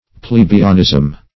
plebeianism - definition of plebeianism - synonyms, pronunciation, spelling from Free Dictionary
Search Result for " plebeianism" : The Collaborative International Dictionary of English v.0.48: Plebeianism \Ple*be"ian*ism\, n. [Cf. F. pl['e]b['e]ianisme.] 1.
plebeianism.mp3